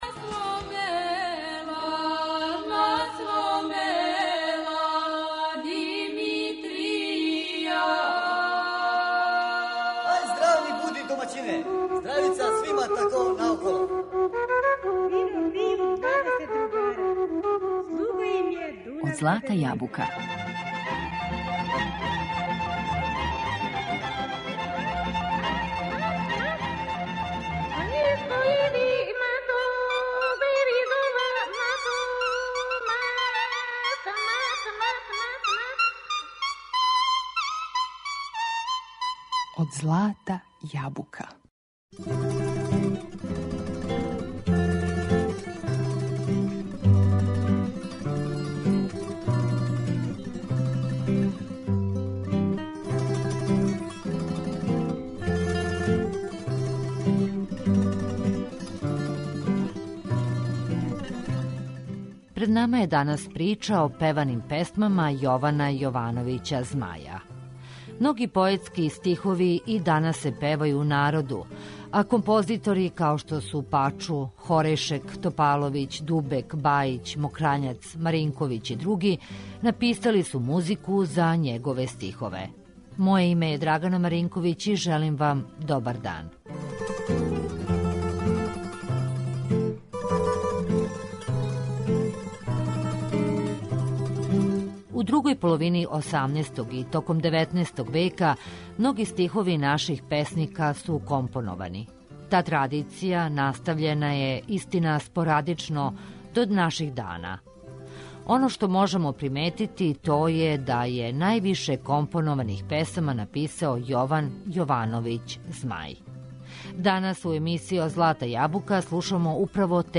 Неке од њих слушаћете у данашњем издању емисије Од злата јабука.